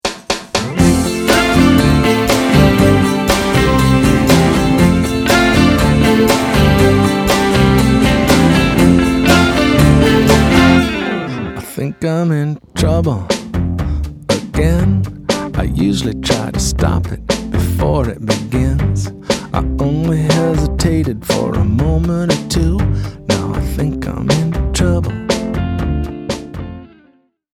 cello
viola
harmony vocals